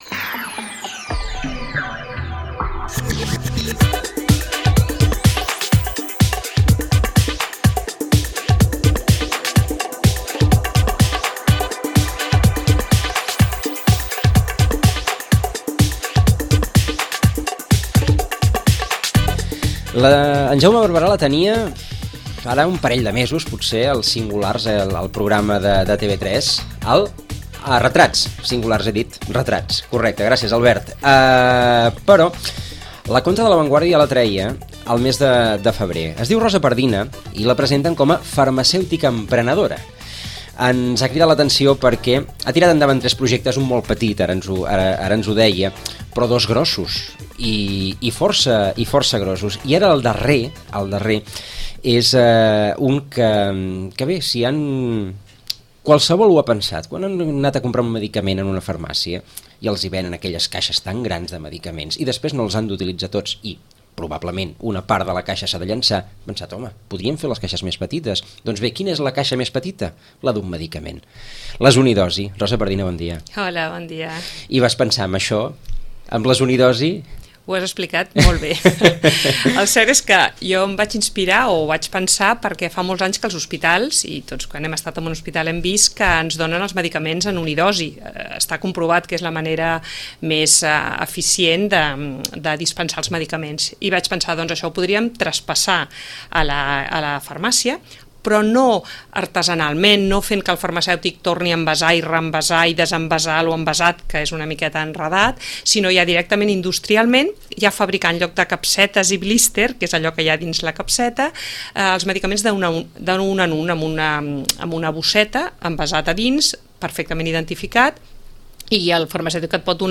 a l’estudi de Ràdio Maricel